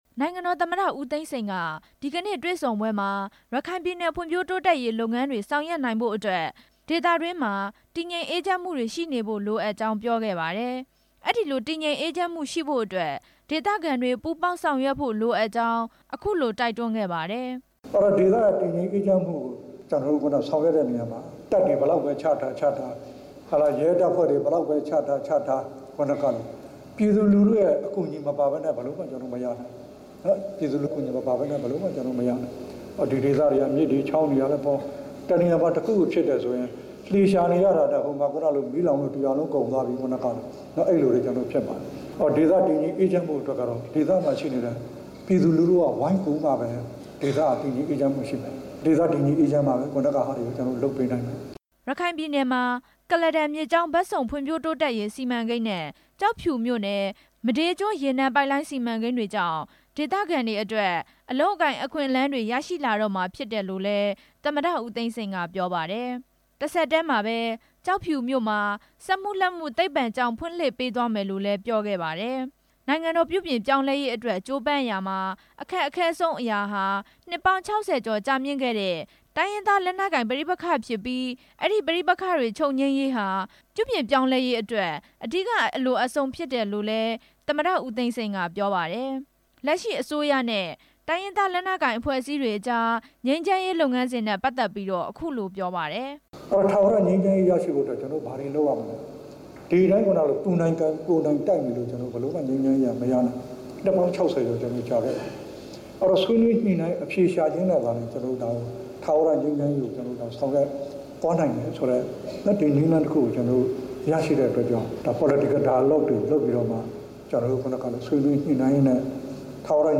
စစ်တွေမြို့ ဦးဥတ္တမခန်းမမှာ ဒေသခံတွေ၊ အရပ်ဘက်လူမှုအဖွဲ့အစည်းတွေ၊ နိုင်ငံရေးပါတီတွေနဲ့ ဒီကနေ့ တွေ့ဆုံစဉ်မှာ နိုင်ငံတော်သမ္မတ ဦးသိန်းစိန်က ရခိုင်ပြည်နယ် ဖံ့ွဖြိုးရေးလုပ်ငန်းတွေ ဆောင်ရွက်နိုင်ဖို့ အတွက် တည်ငြိမ်အေးချမ်းမှုရှိဖို့ လိုအပ်ကြောင်း ရှင်းပြစဉ် ထည့်သွင်းပြောခဲ့တာပါ။